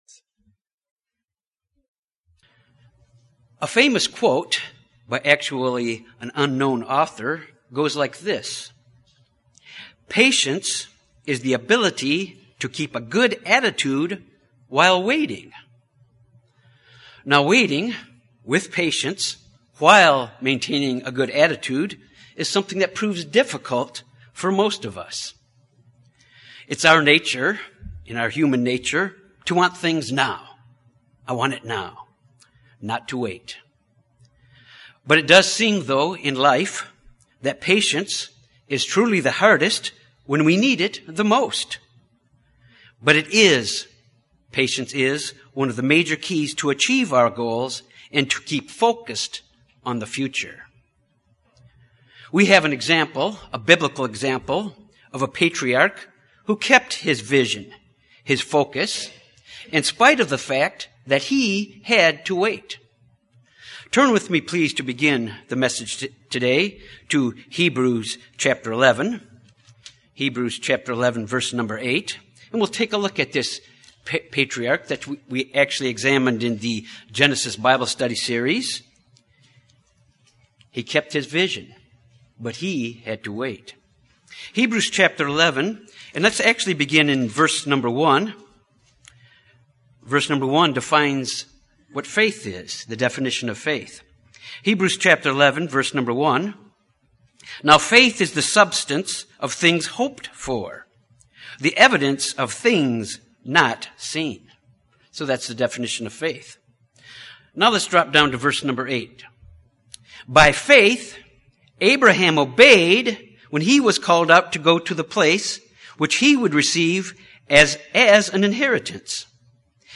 This sermon examines why we must misunderstand what patience is and the source of patience in our lives.
Given in Little Rock, AR